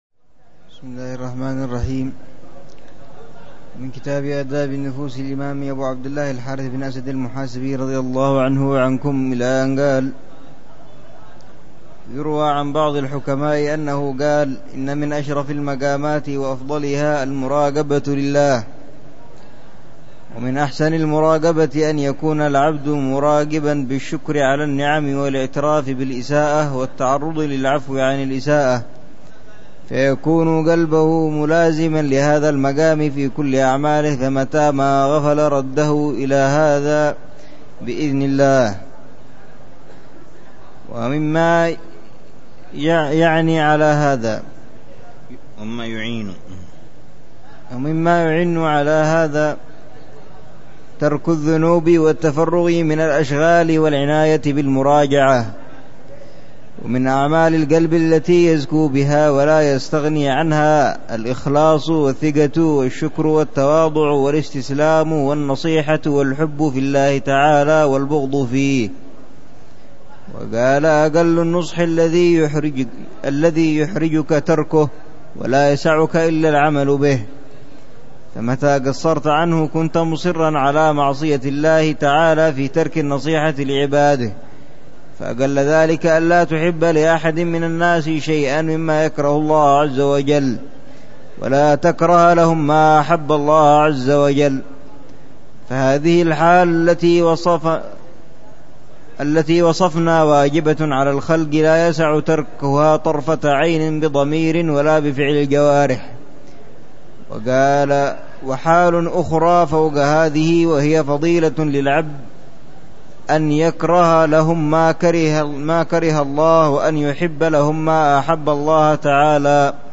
الدرس الثامن من دروس الحبيب عمر بن حفيظ في شرح كتاب آداب النفوس للإمام أبي عبد الله الحارث المحاسبي، يتحدث عن أهمية تهذيب النفس وتزكيتها والنه